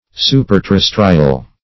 Search Result for " superterrestrial" : The Collaborative International Dictionary of English v.0.48: Superterrestrial \Su`per*ter*res"tri*al\, a. Being above the earth, or above what belongs to the earth.